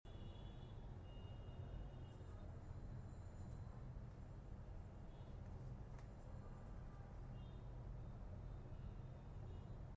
Wgp mini UPS sound effects free download